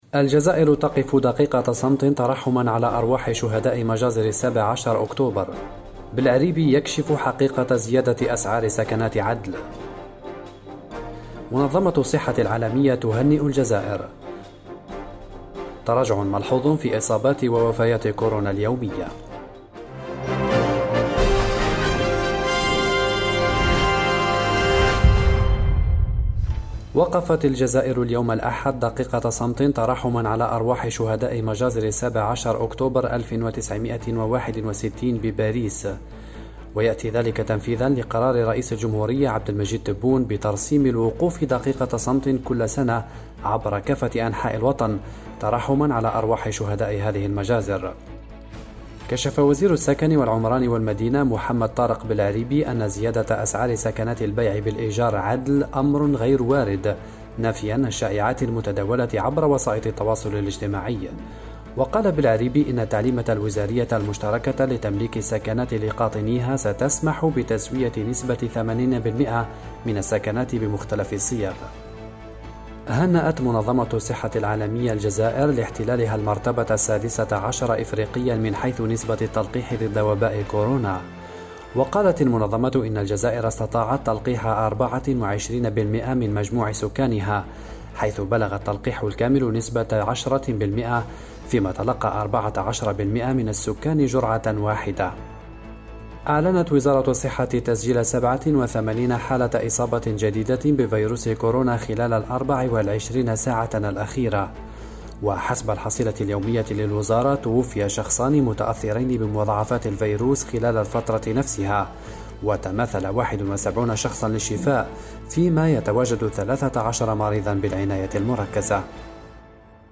النشرة الرقميةفي دقيقتين